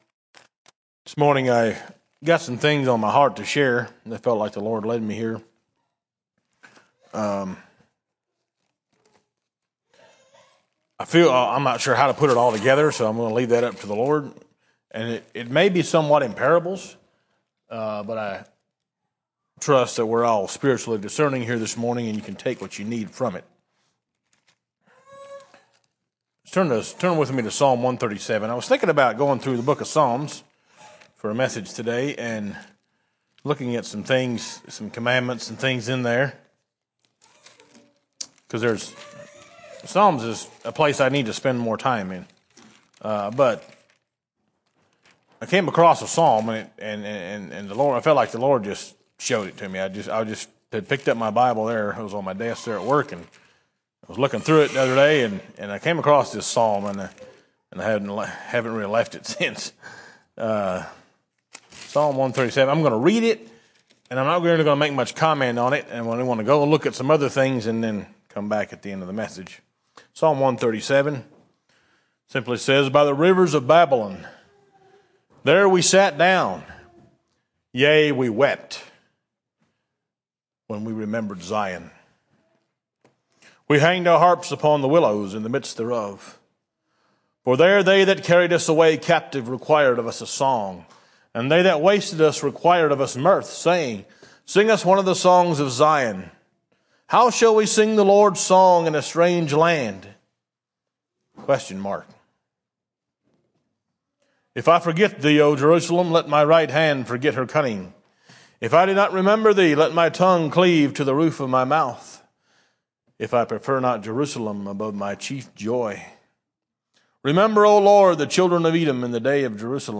A Sermon By